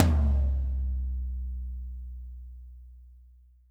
-TOM 2G   -L.wav